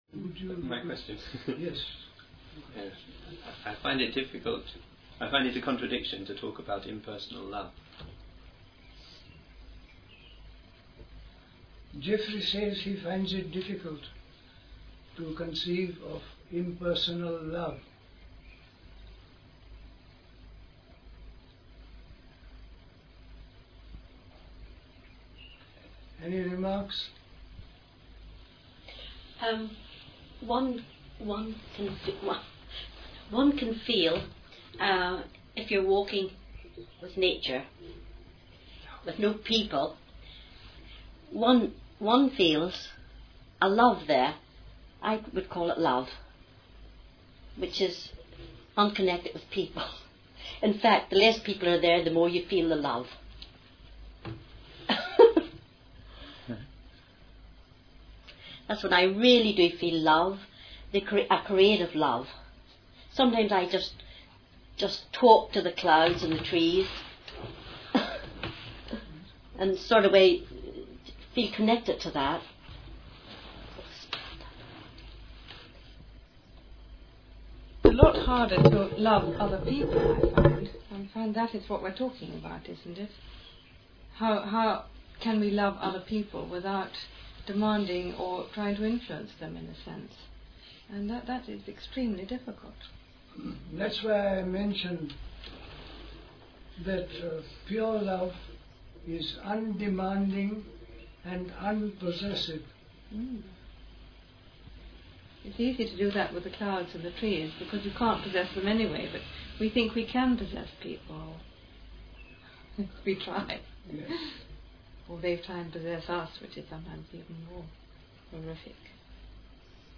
Questions and Discussion: Impersonal Love